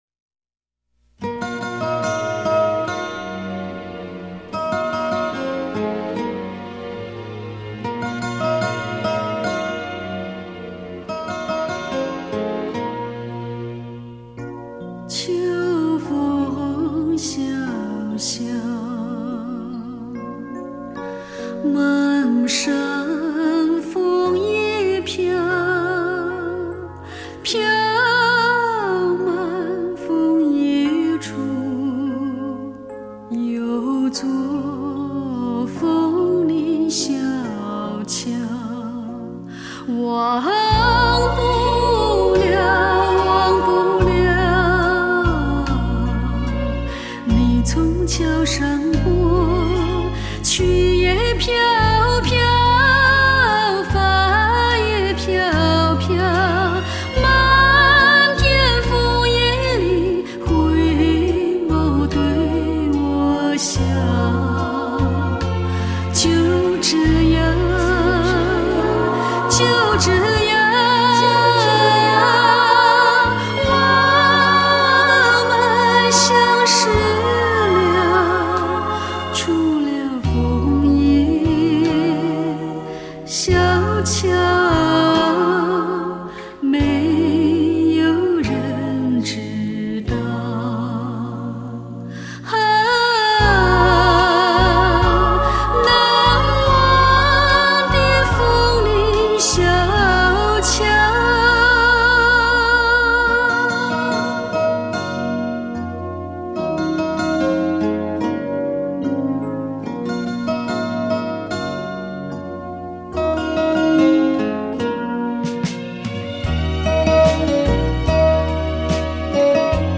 新古典的浪漫 后现代的抒情
她的歌，用温柔浅唱 她的歌，在永恒转航